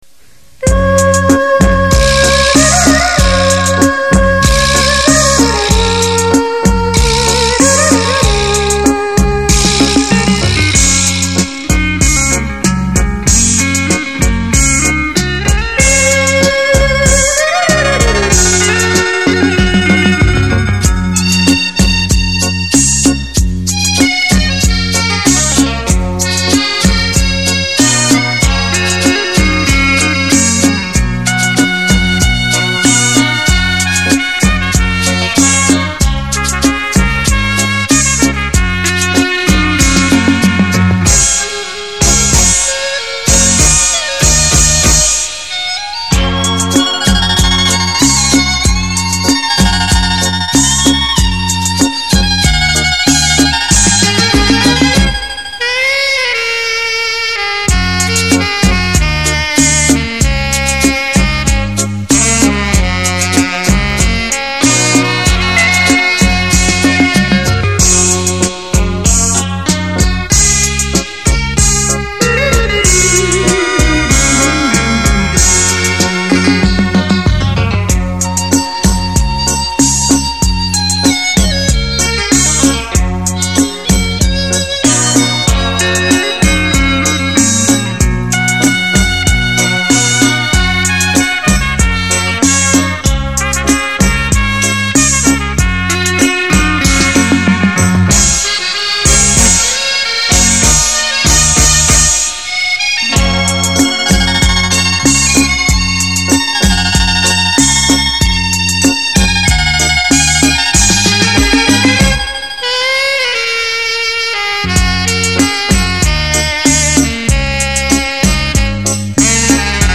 本专辑是一张根据日本地方民谣小曲改编的情调轻音乐唱片。
由吉马电子琴、电吉它在配以竹笛、二胡、古筝等乐器与架
子鼓的奇妙组合。演译出节奏鲜明、曲调逶婉、缠绵而略带
伤感的情调。